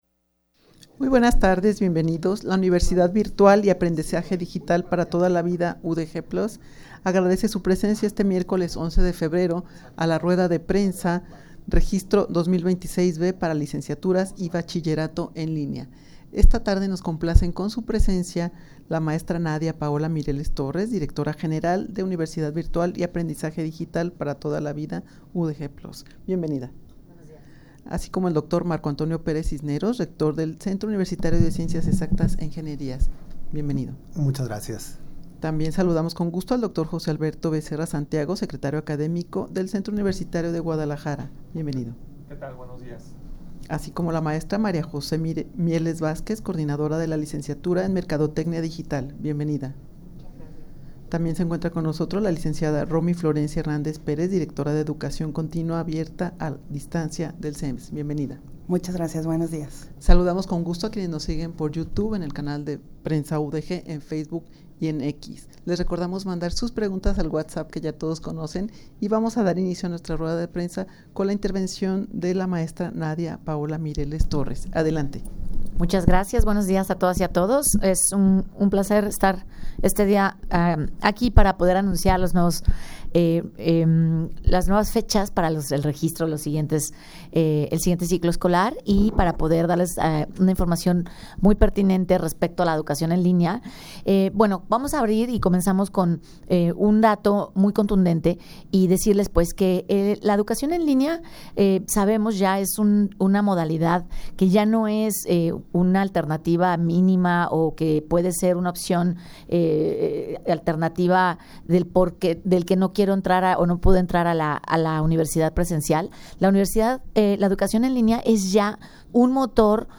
Audio de la Rueda de Prensa
rueda-de-prensa-registro-2026-b-para-licenciaturas-y-bachillerato-en-linea.mp3